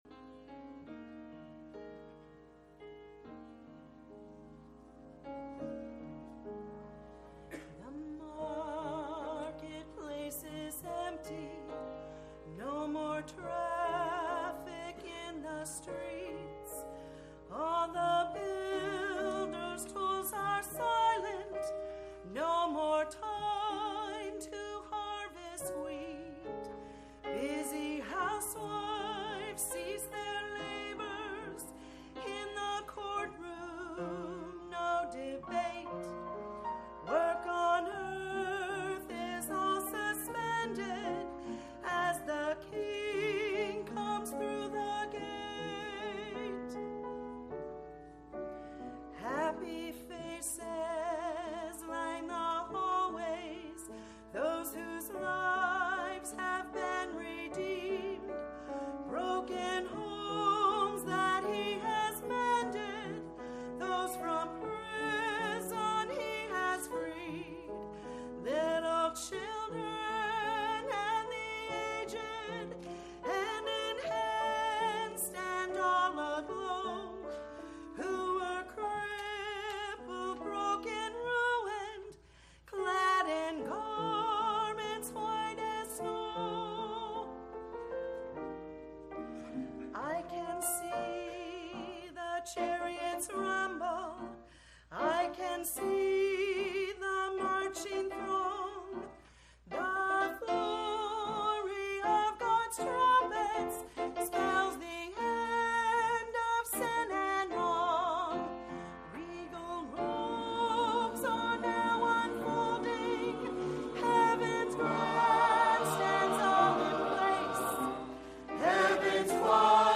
Sermons – Pioneer Baptist Church of Citrus Heights, CA